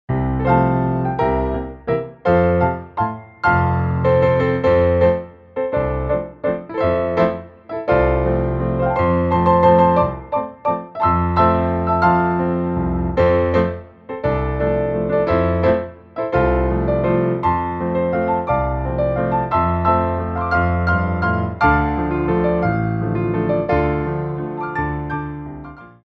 Grand Allegro
3/4 (16x8)